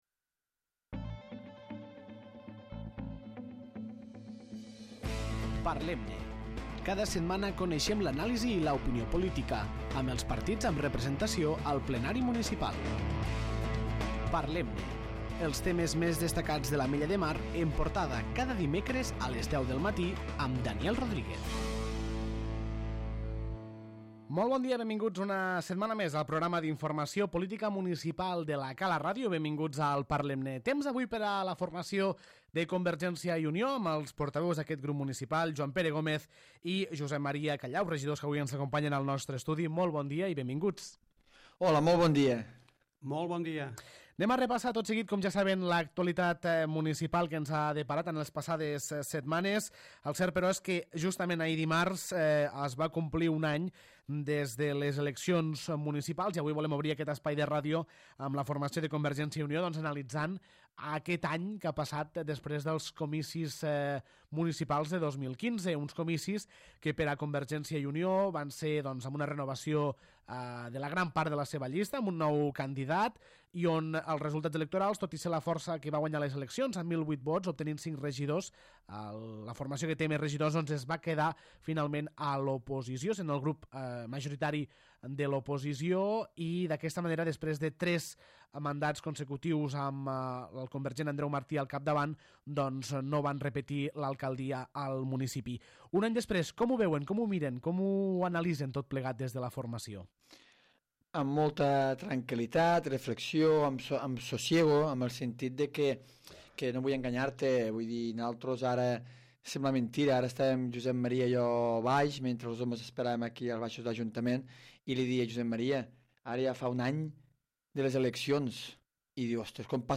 Avui, al Parlem-ne, ens han acompanyat els regidors del Grup Municipal de CiU, Joan Pere Gómez i Comes i Josep Maria Callau.